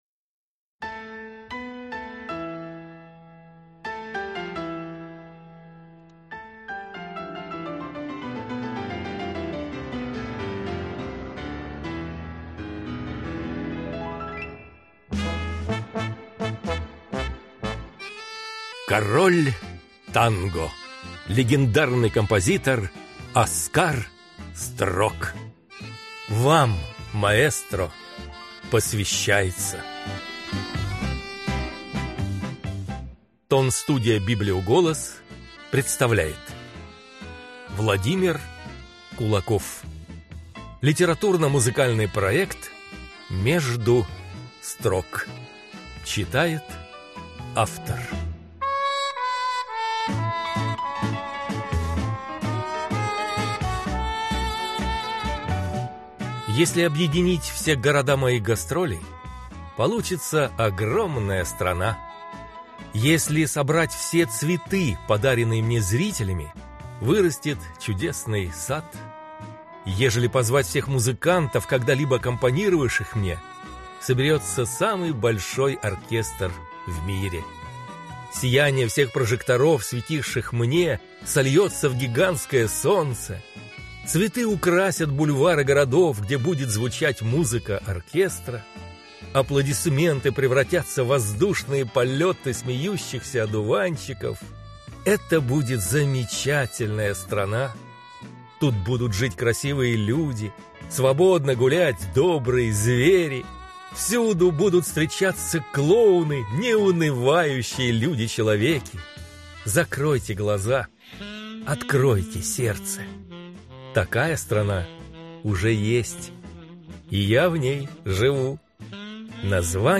Аудиокнига Между строк | Библиотека аудиокниг